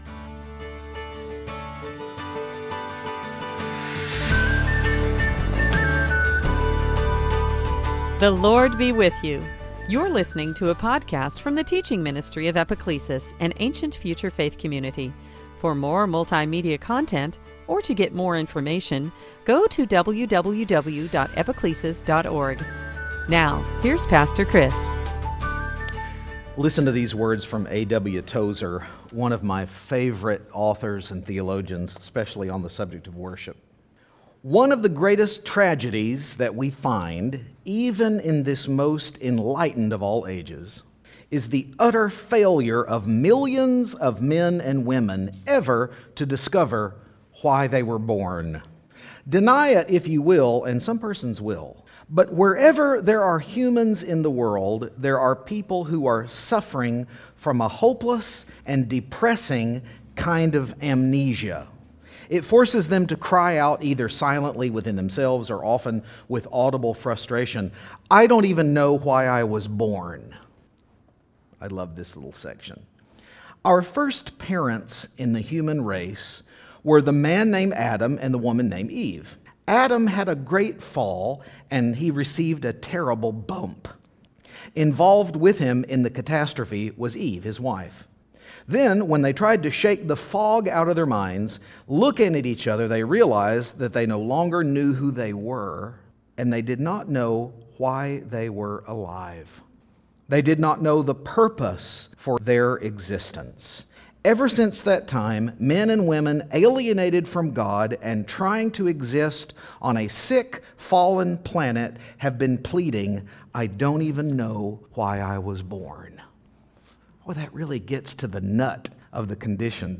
Series: Sunday Teaching In this Season after Pentecost